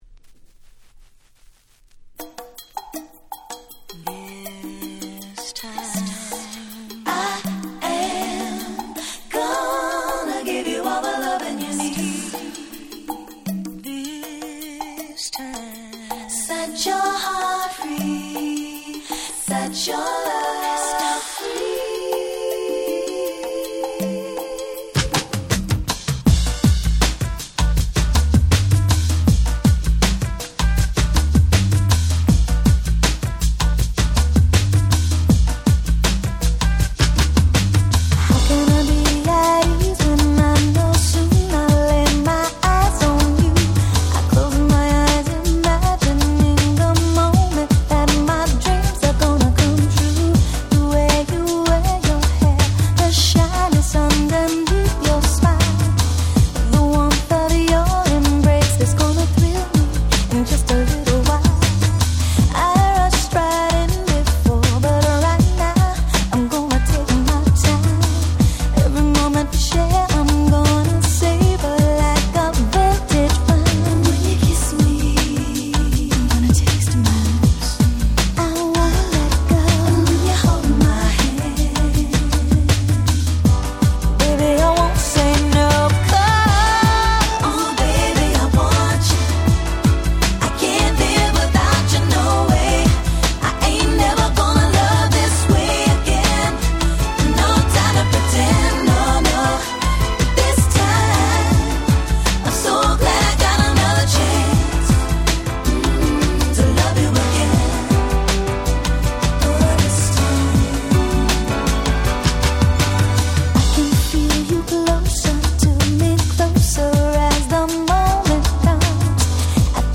95' Smash Hit R&B !!